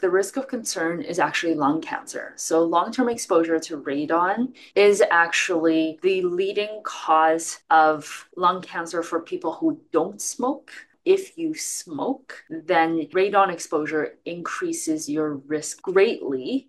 And the Doctor says there are some very serious risks posed by the gas.